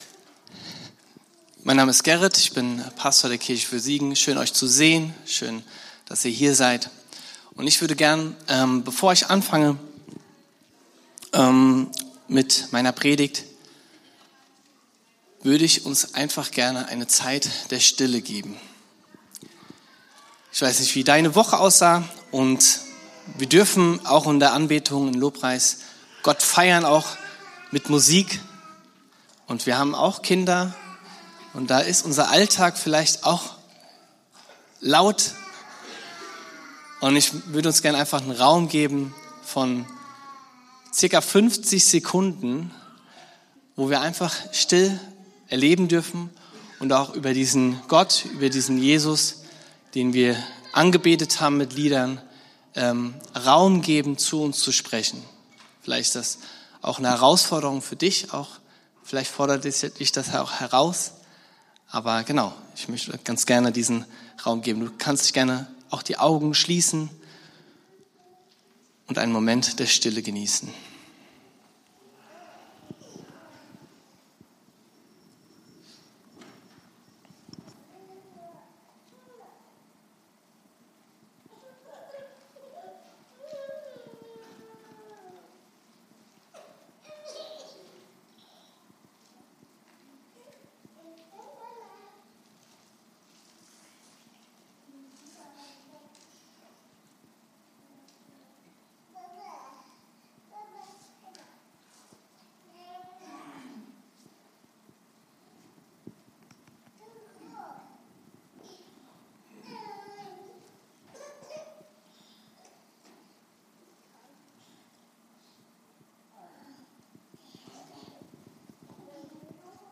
Predigt vom 19.04.2026 in der Kirche für Siegen